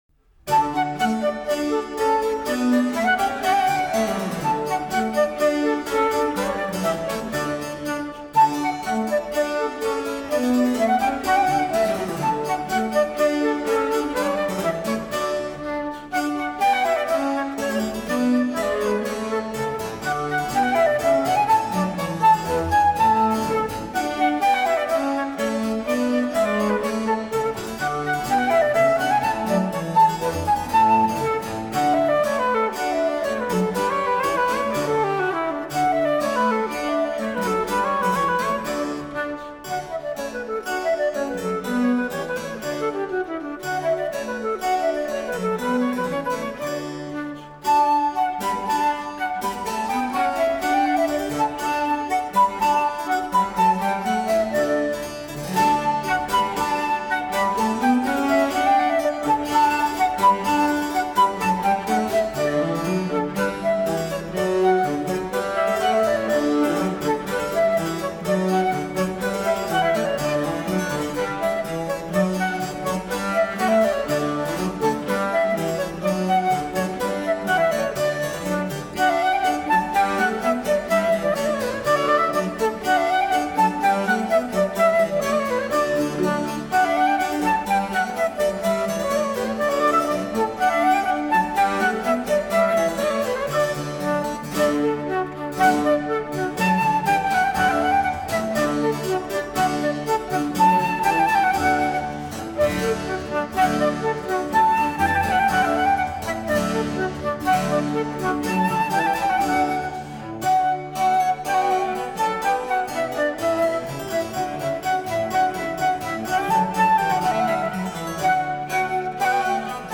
Flute
Harpsichord
Viol
Baroque Viola